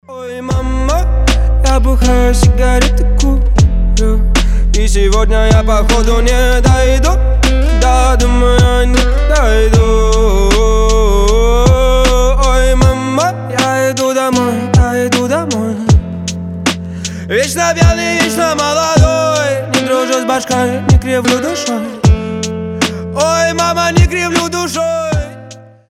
мужской вокал
грустные
русский рэп